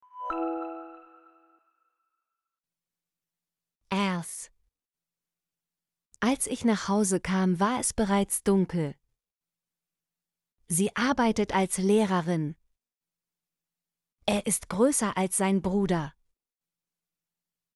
als - Example Sentences & Pronunciation, German Frequency List